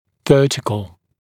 [‘vɜːtɪkl][‘вё:тикл]вертикальный